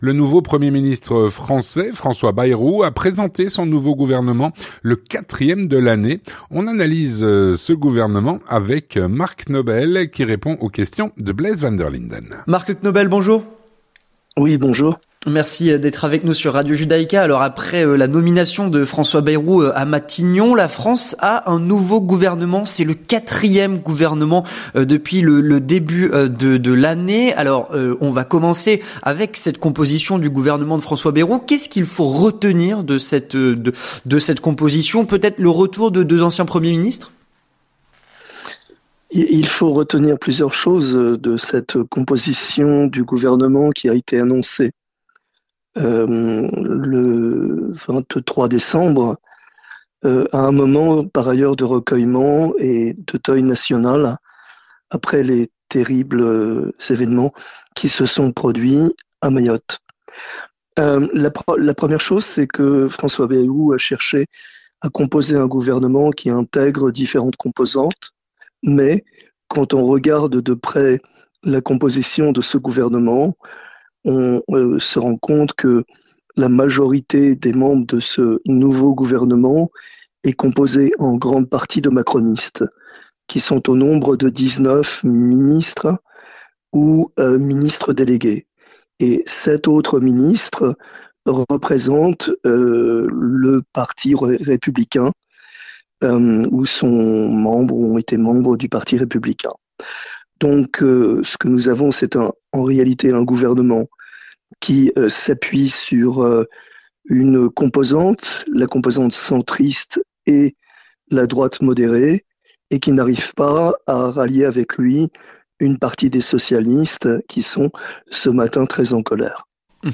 L'entretien du 18H - La France tient son gouvernement.